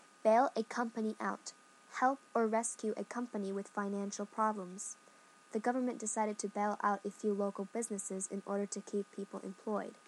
英語ネイティブによる発音は下記のリンクから聞きことができます。